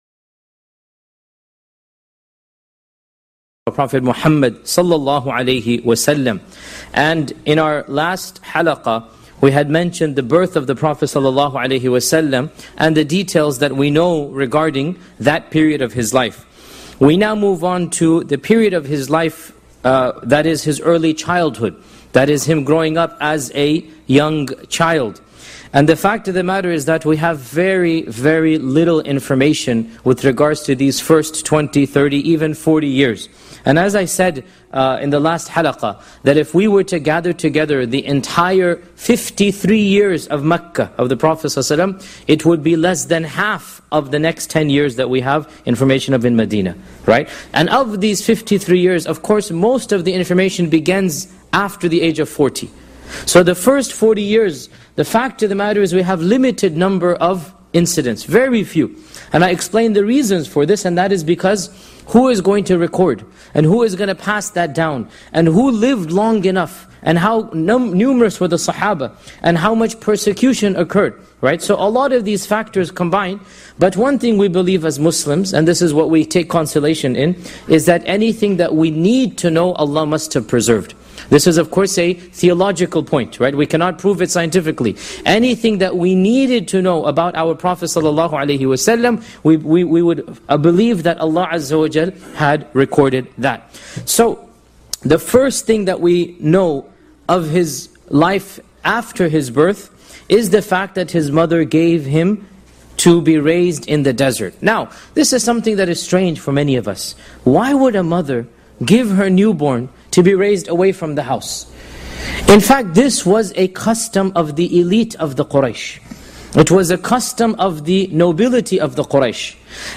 Shaykh Yasir Qadhi gives a detailed analysis of the life of Prophet Muhammad (peace be upon him) from the original sources.
This Seerah lecture explores the early childhood of Prophet Muhammad (peace be upon him), focusing on the fascinating Qurayshi tradition of sending newborns to be raised in the desert by Bedouin wet nurses.